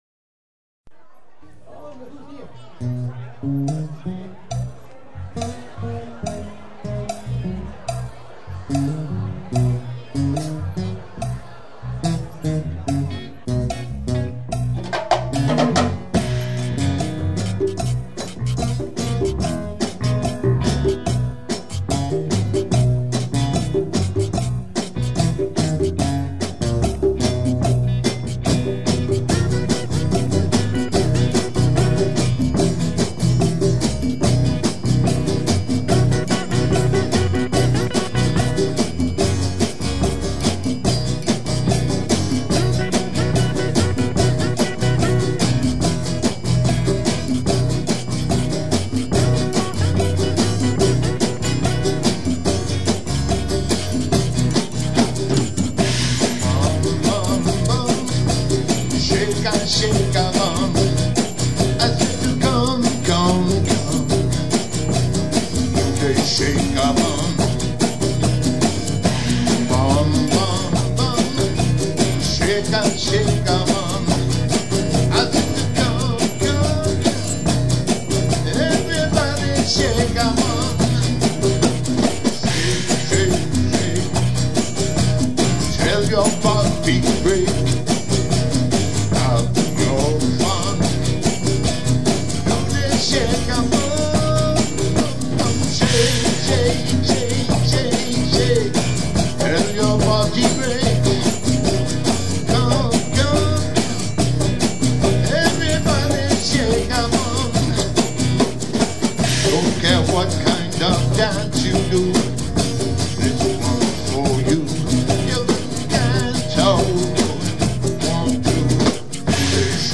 rough mix (pre back ground vocals)
somewhat tantric and liberating mantra